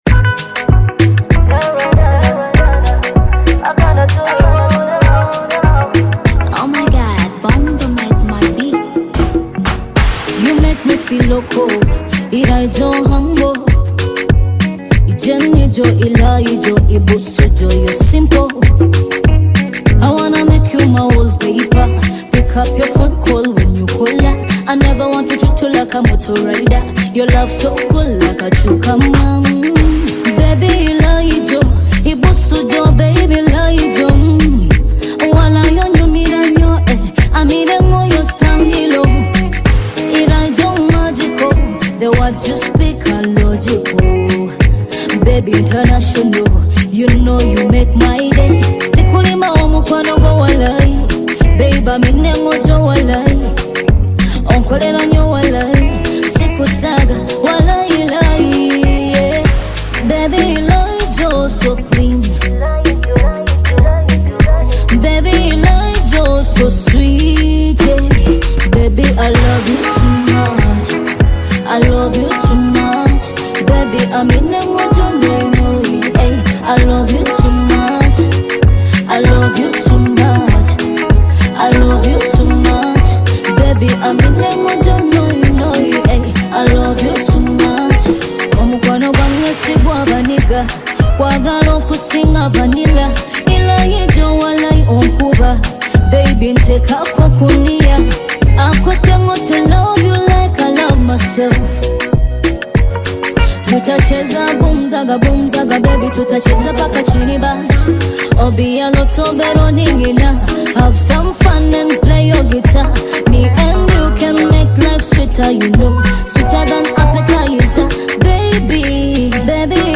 smooth vocals and infectious energy